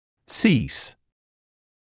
[siːs][сиːс]прекращать, останавливать